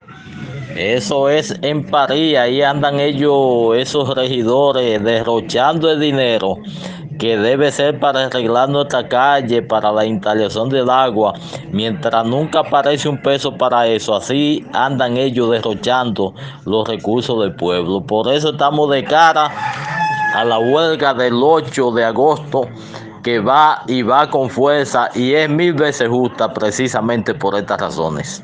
Grabación integral